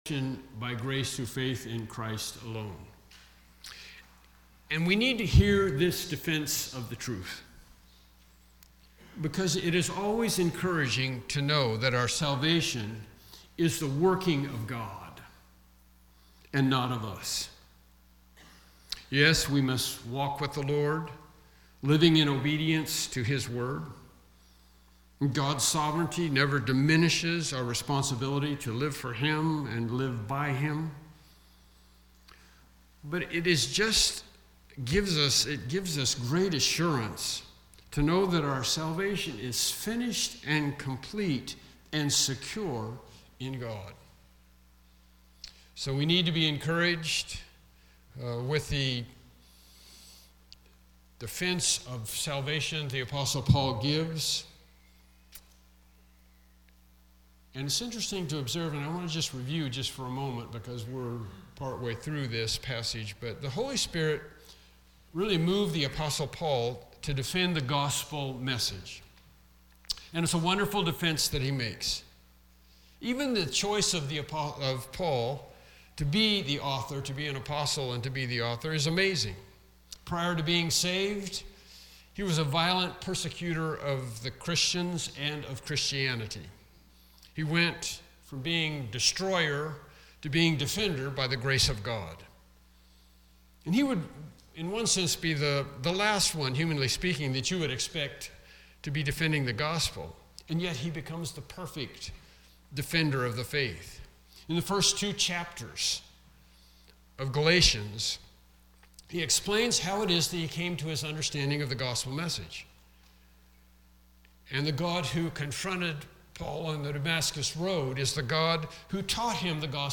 Passage: Galatians 3:18-19 Service Type: Morning Worship Service « Lesson 13- Living by Sight The Interludes in the Revelation No. 4